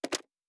pgs/Assets/Audio/Sci-Fi Sounds/Mechanical/Device Toggle 01.wav at master
Device Toggle 01.wav